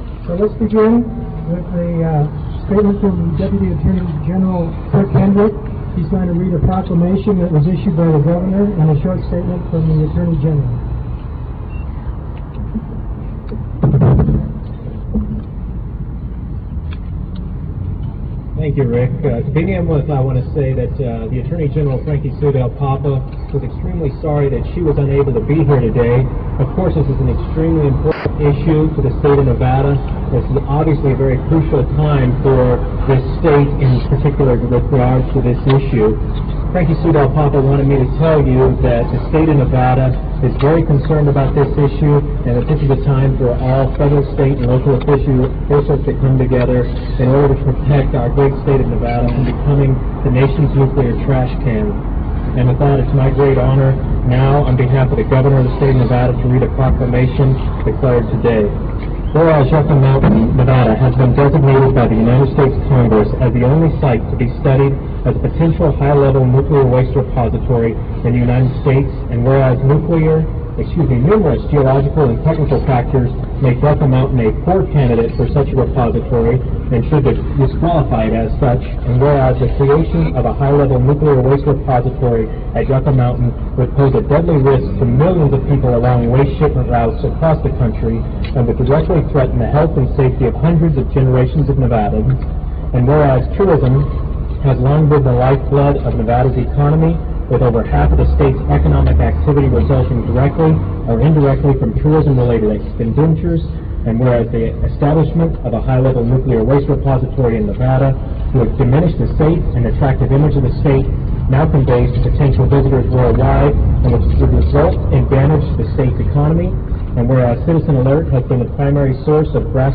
02. press conference